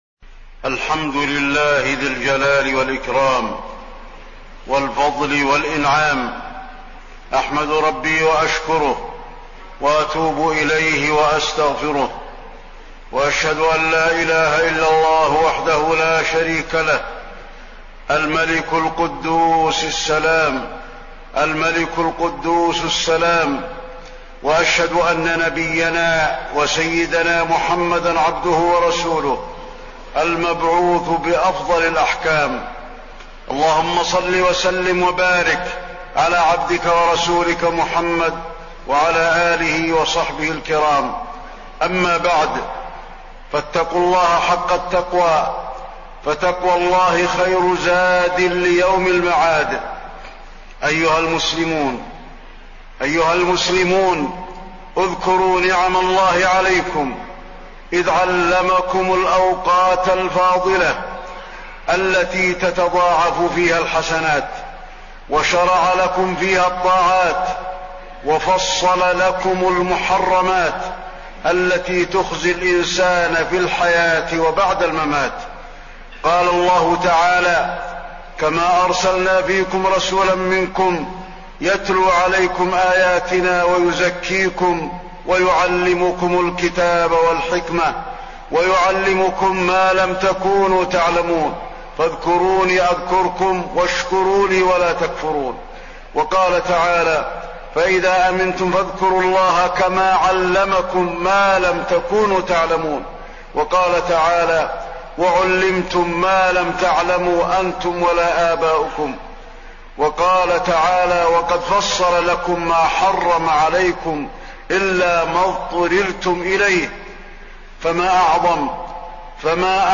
تاريخ النشر ٢٨ شعبان ١٤٣٢ هـ المكان: المسجد النبوي الشيخ: فضيلة الشيخ د. علي بن عبدالرحمن الحذيفي فضيلة الشيخ د. علي بن عبدالرحمن الحذيفي رمضان واغتنام الأوقات الفاضلة